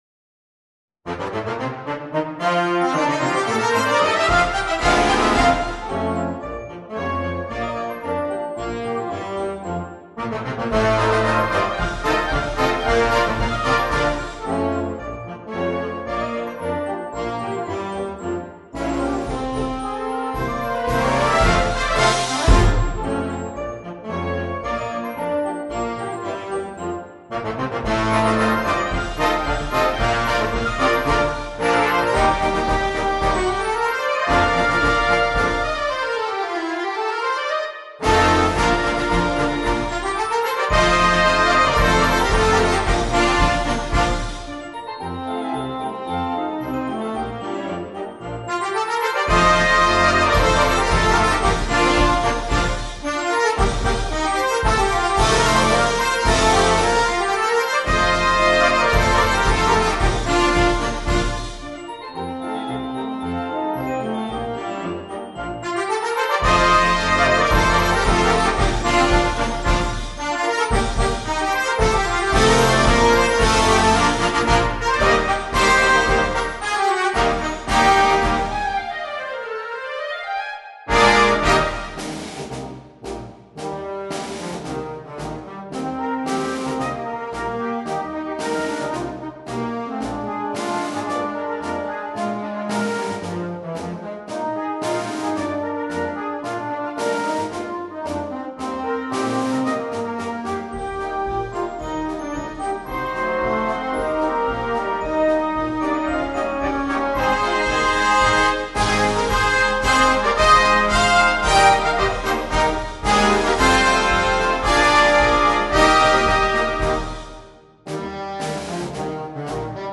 MARCE DA SFILATA
MUSICA PER BANDA
marcia Una bella marcia per iniziare il concerto.